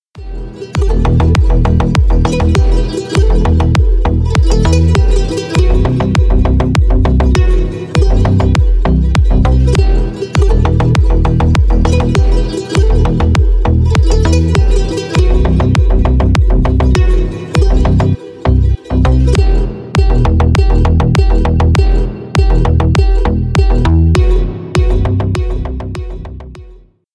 Ремикс # Танцевальные
без слов